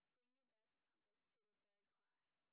sp27_street_snr20.wav